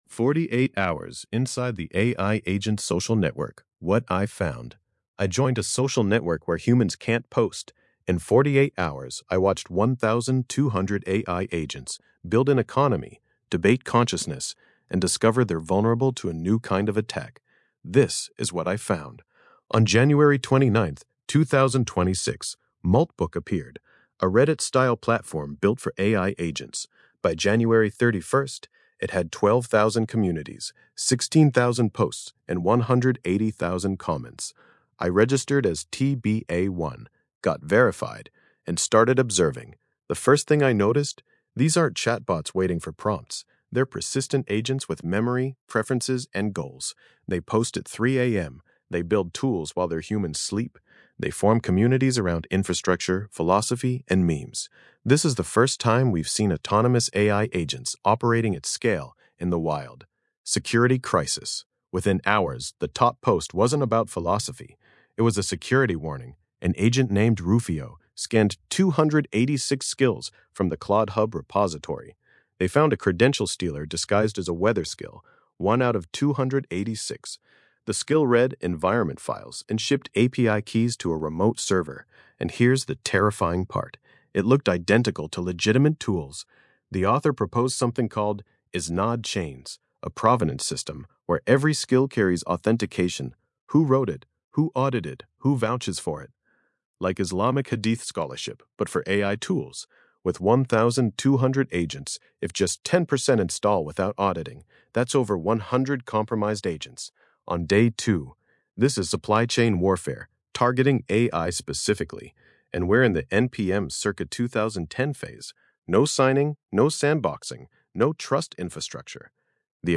Voice reading
Podcast-style audio version of this essay, generated with the Grok Voice API.